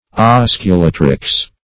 Search Result for " osculatrix" : The Collaborative International Dictionary of English v.0.48: Osculatrix \Os`cu*la"trix\, n.; pl. Osculatrixes .